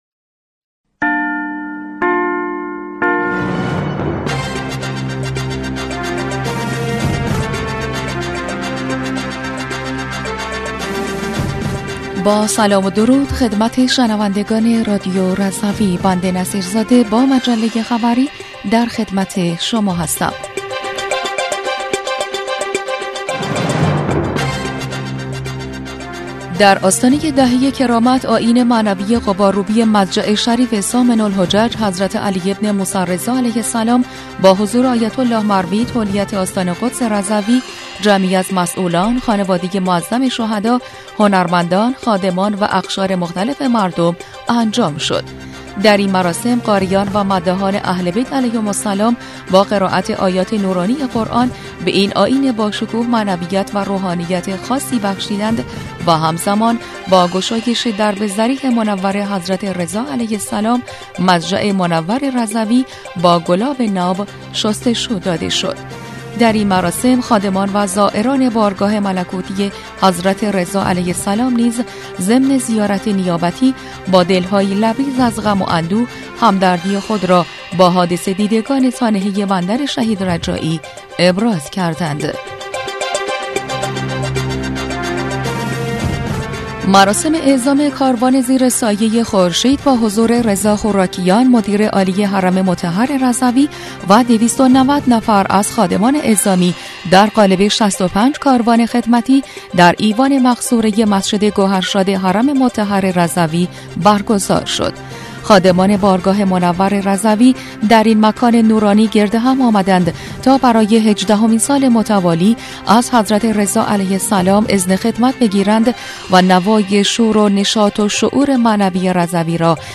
بسته خبری 10اردیبهشت1404 رادیو رضوی؛